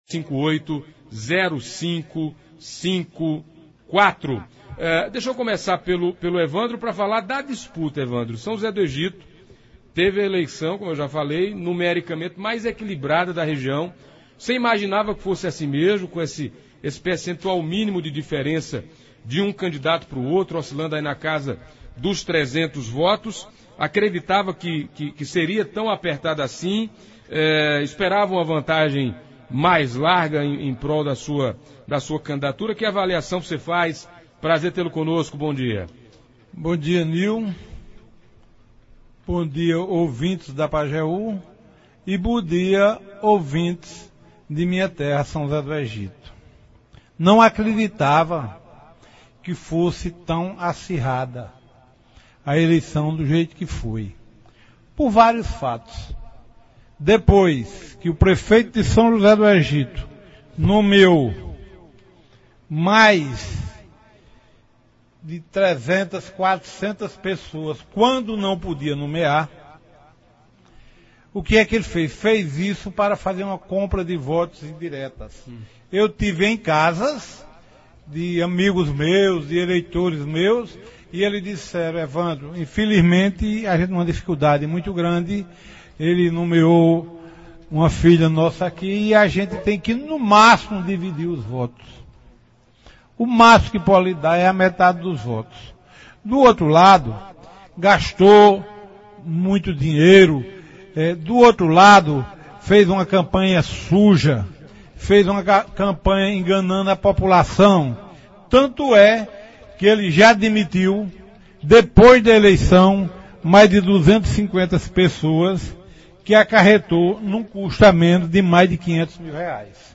Os prefeitos eleitos de São José do Egito e Itapetim, Evandro Valadares e Adelmo Moura, ambos do PSB, estiveram nesta terça (25) no Debate das Dez do programa Manhã Total, da Rádio Pajeú. Na pauta, os desafios administrativos que terão nas suas cidades a partir de 2017.